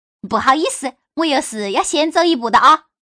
Index of /hunan_feature2/update/1271/res/sfx/changsha_woman/